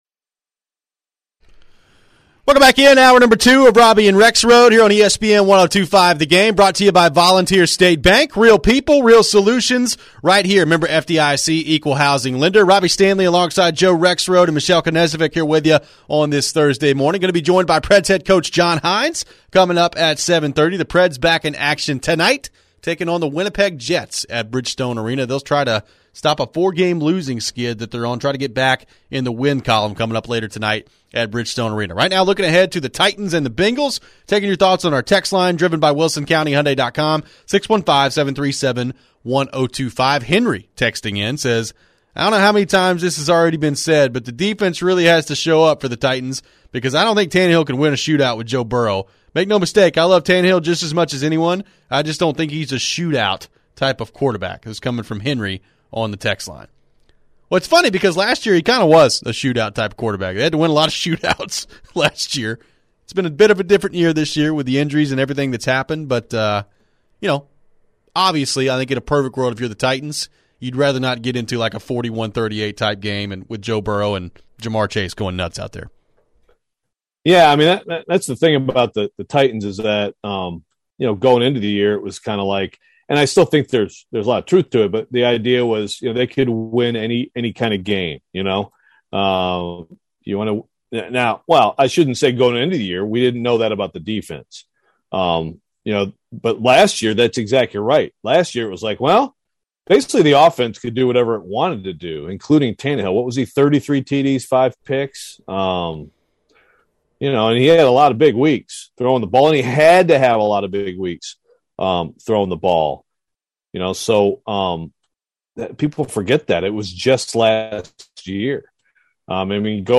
Could he be the next assistant to get plucked for a head coaching job? We're then joined by Predators head coach John Hynes and he tells us what has played a factor into this 4 game slump. Finally we've got the Best and Worst in sports for the week.